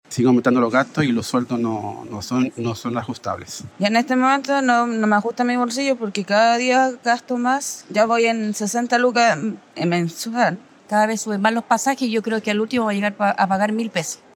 Ante este panorama, los usuarios del transporte público respondieron con molestia al alza, argumentando que el aumento no se acomoda con sus remuneraciones y proyectando que, a la larga, las tarifas solo seguirán creciendo.